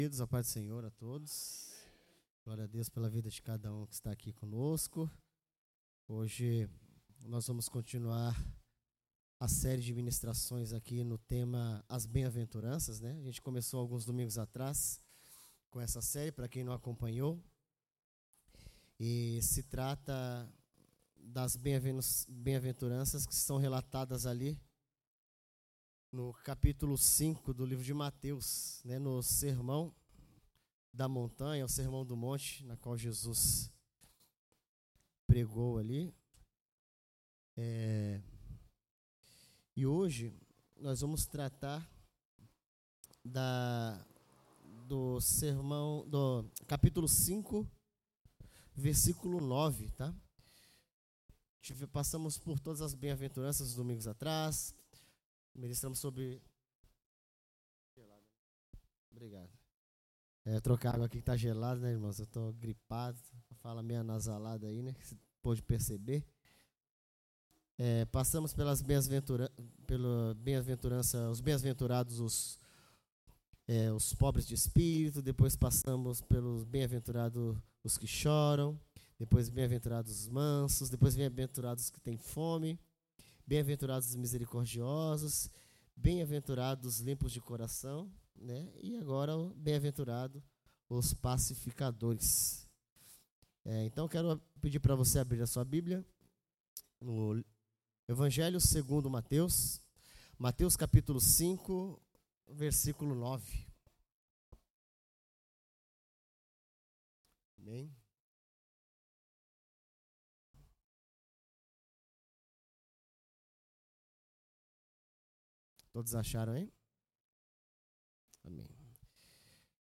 sermao-mateus-5-9.mp3